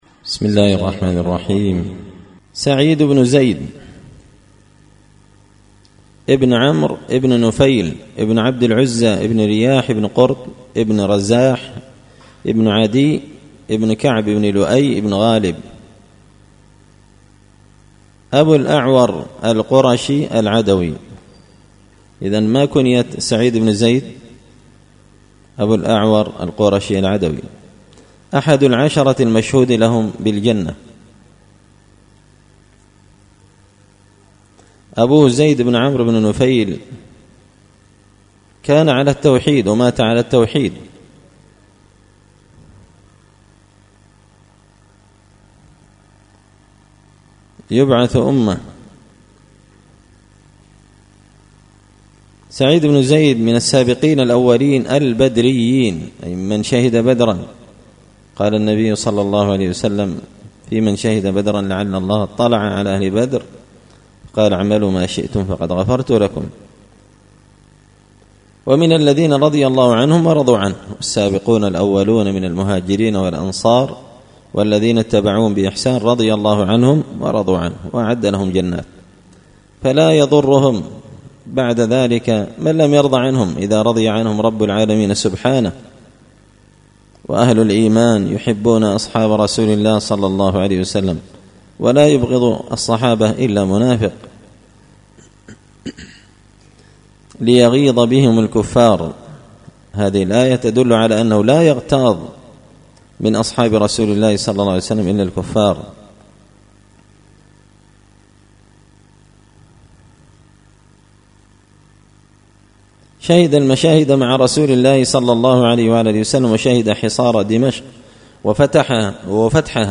قراءة تراجم من تهذيب سير أعلام النبلاء _الدرس 6سعيد بن زيد